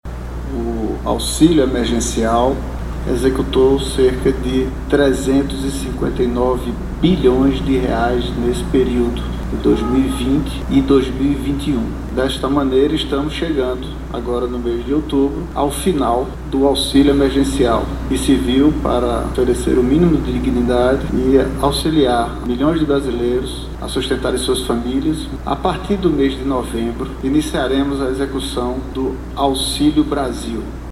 Sonora-Joao-Roma_ministro-da-Cidadania.mp3